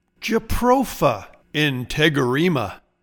Pronounciation:
Jat-RO-fa in-teg-er-REE-muh